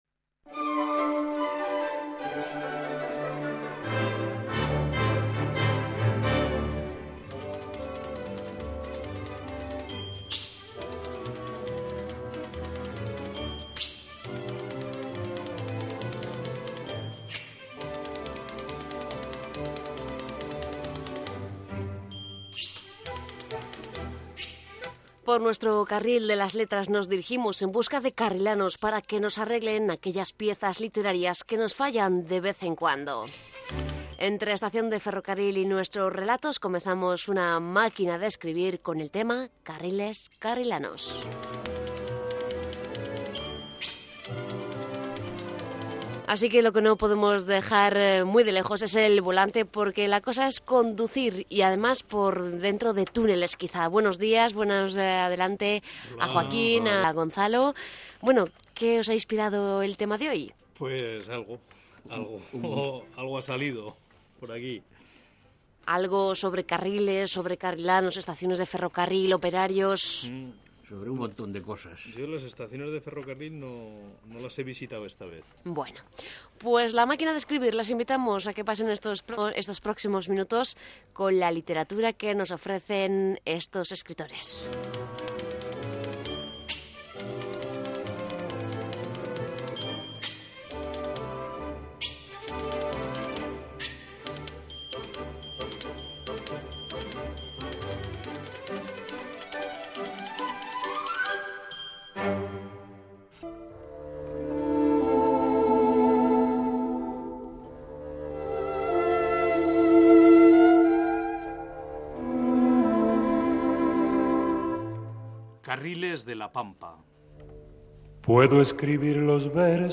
La idea original era escribir cada semana sobre un tema diferente, bien en prosa o bien en poesía y leer cada uno sus textos en el estudio de Radio Sobrarbe.